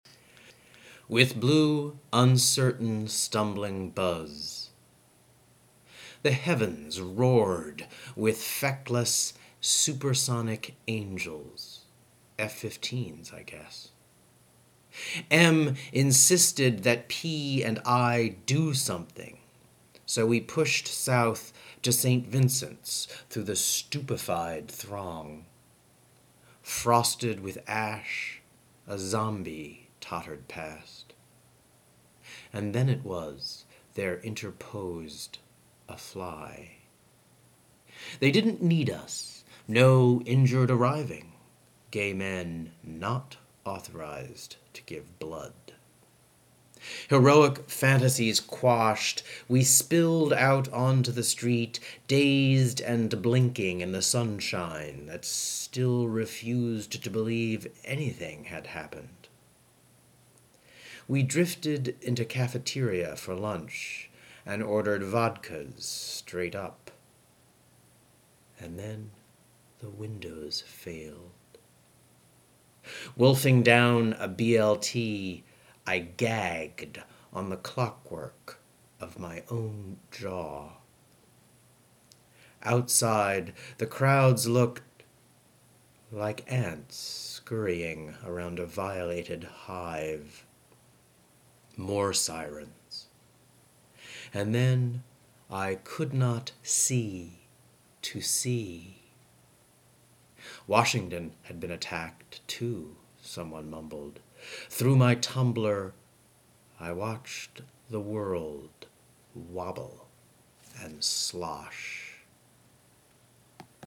read his poem